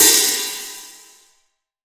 Index of /90_sSampleCDs/AKAI S6000 CD-ROM - Volume 3/Hi-Hat/14INCH_FLANGE_HI_HAT